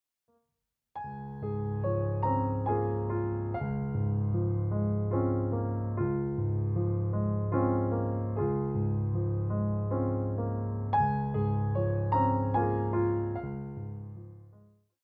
With its steady, expressive piano style